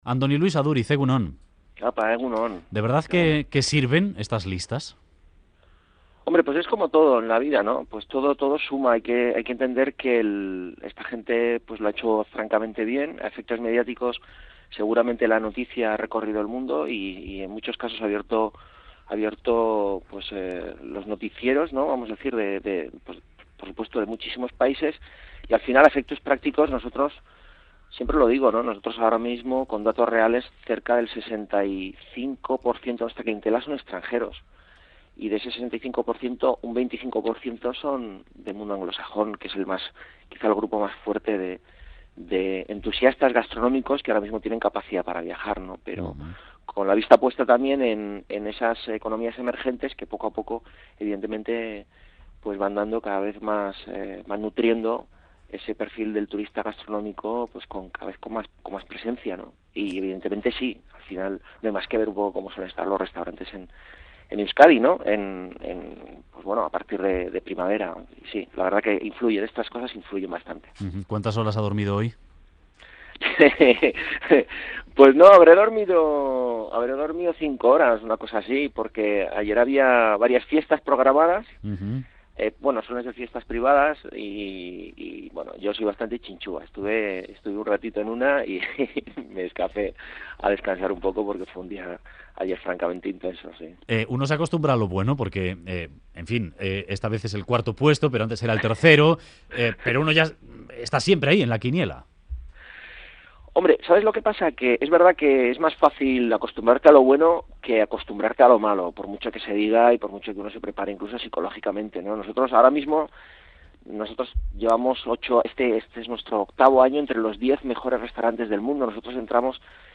Entrevista a Aduriz | Mugaritz entre los 50 mejores restaurantes
Boulevard ha charlado con Andoni Luis Aduriz, de Mugaritz, cuarto en el ranking de los mejores restaurantes del mundo.